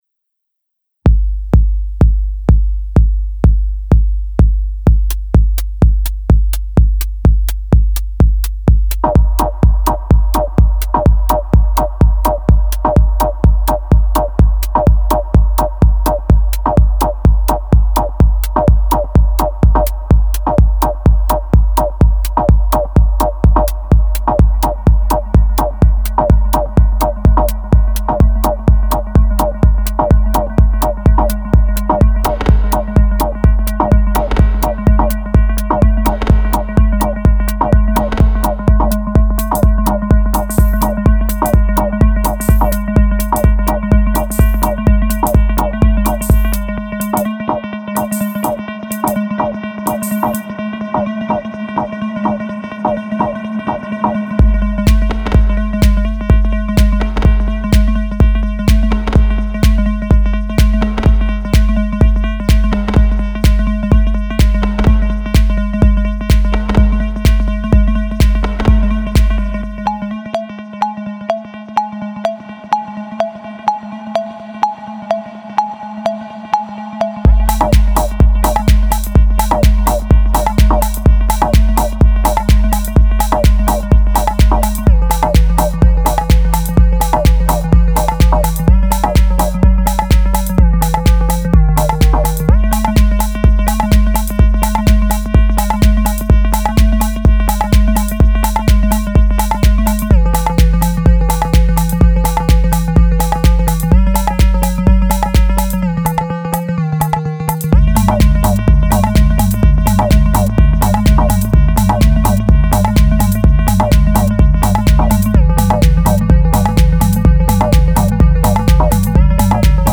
minimal housy remix